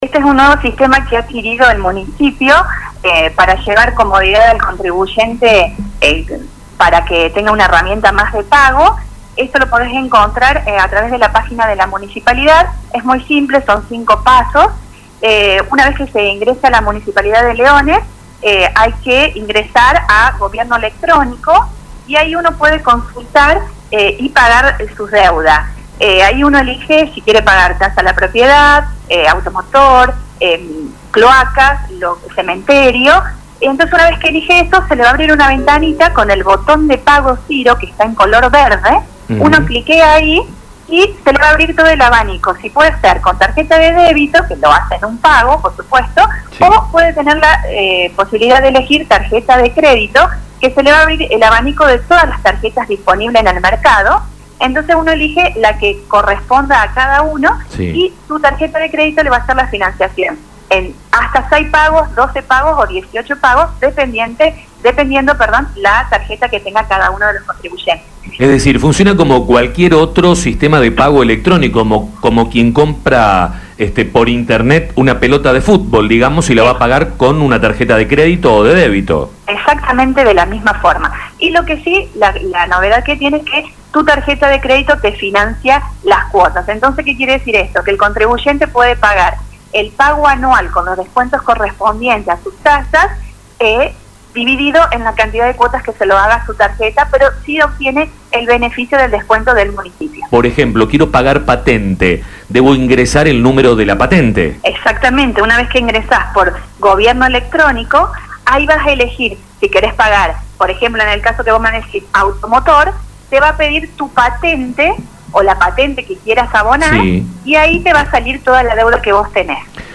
En esta nota, la contadora explicó su funcionamiento.